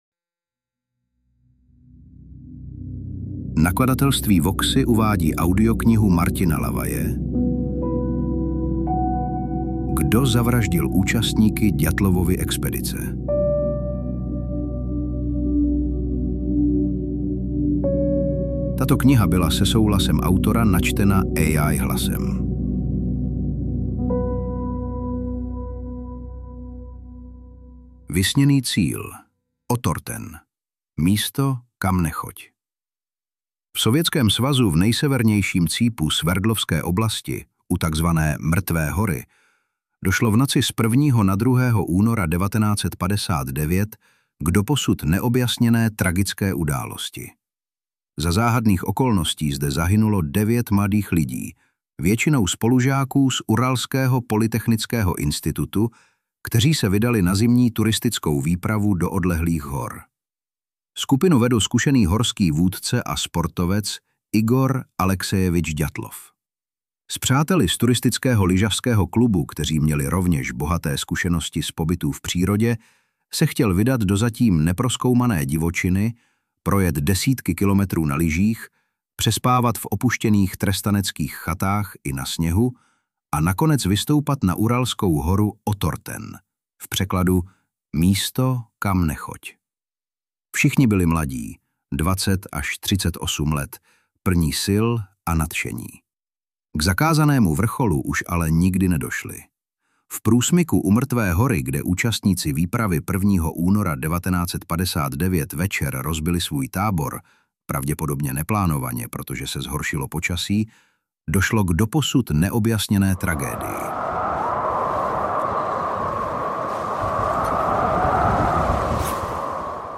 Interpret:  hlas AI
AudioKniha ke stažení, 13 x mp3, délka 5 hod. 18 min., velikost 291,0 MB, česky